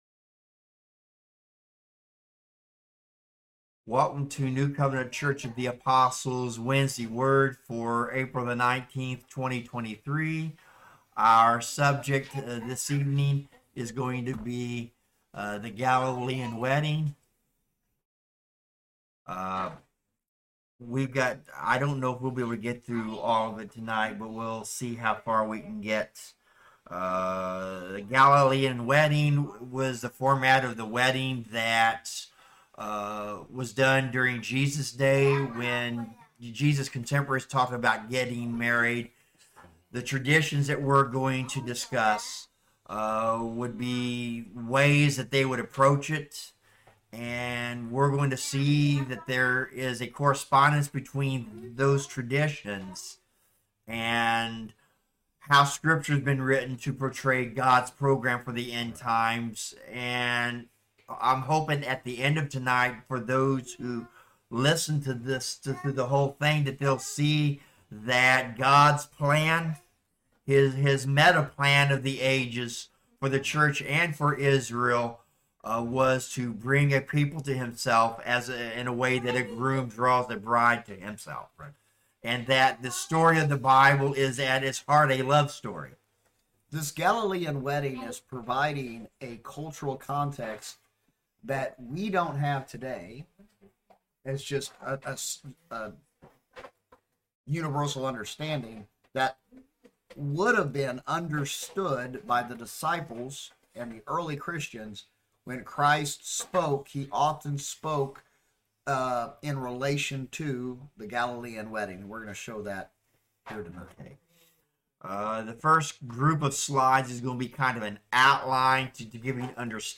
2023 The Galilean Wedding Service Type: Wednesday Word Bible Study In this installment of Wednesday Word